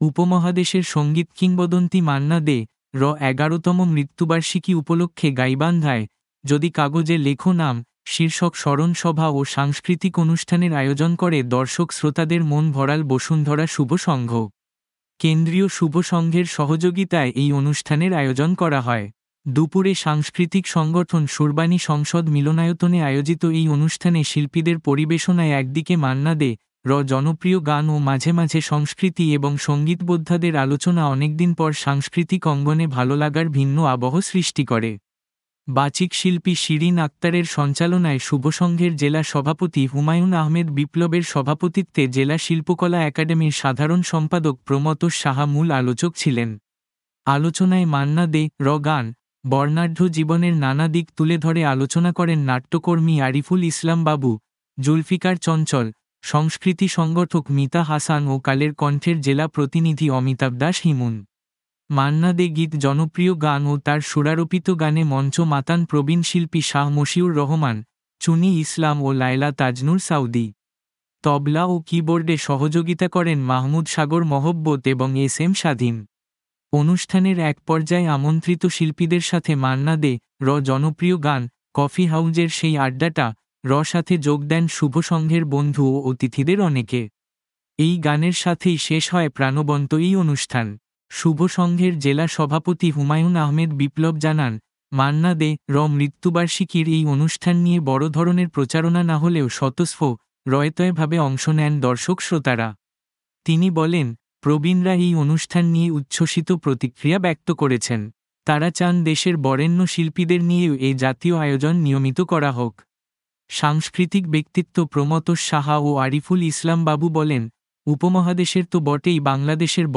স্মরণ ও সাংস্কৃতিক অনুষ্ঠান
দুপুরে সাংস্কৃতিক সংগঠন সুরবাণী সংসদ মিলনায়তনে আয়োজিত এই অনুষ্ঠানে শিল্পীদের পরিবেশনায় একদিকে মান্না দে’র জনপ্রিয় গান ও মাঝে মাঝে সংস্কৃতি এবং সঙ্গীতবোদ্ধাদের আলোচনা অনেকদিন পর সাংস্কৃতিক অঙ্গণে ভাললাগার ভিন্ন আবহ সৃষ্টি করে।
তবলা ও কী বোর্ডে